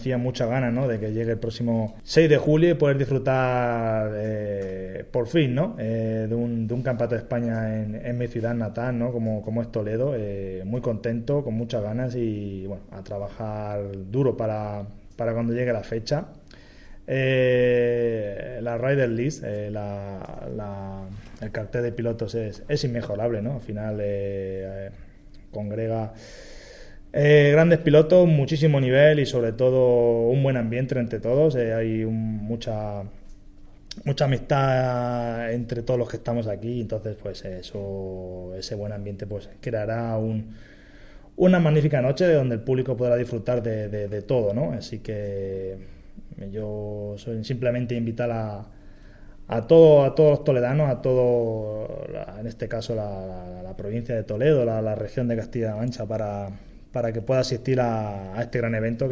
El concejal de Festejos y Juventud, José Vicente García-Toledano ha presentado hoy el campeonato de España Freestyle de Motocross que se desarrollará en la plaza de toros el próximo 6 de julio.